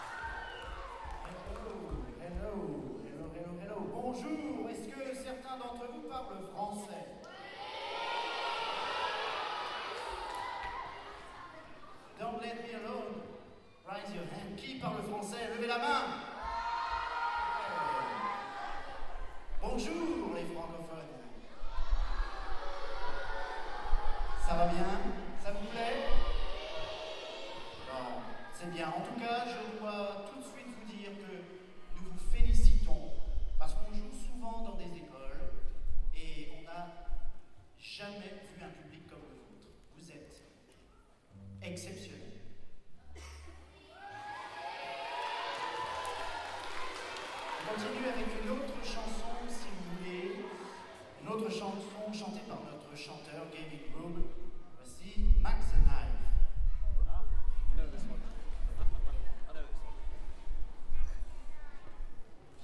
I remember this concert; it was in a grade school gymnasium in Luxembourg and as you can hear, those kids were fantastic.